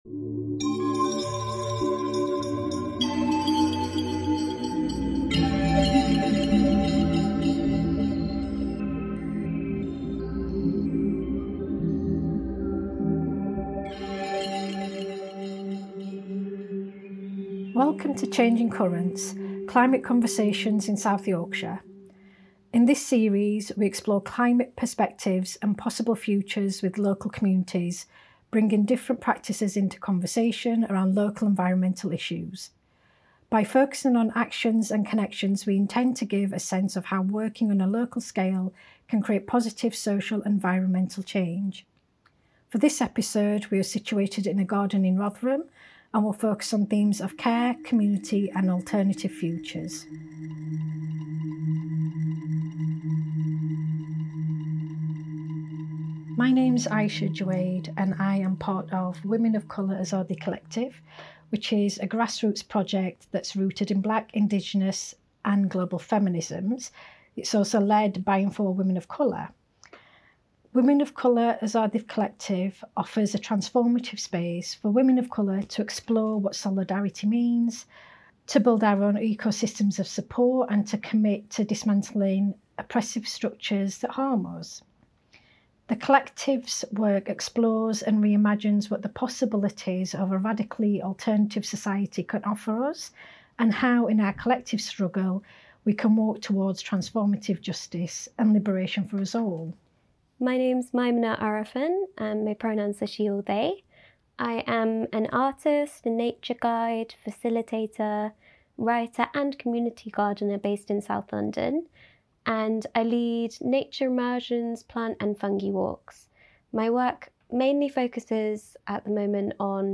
Changing Currents is a new podcast series exploring climate perspectives and possible futures featuring the voices of artists, growers, activists, local community groups, heritage workers and researchers across South Yorkshire.
This conversation included exploring a ‘secret garden’ in Rotherham, foraging elderflower and making tea.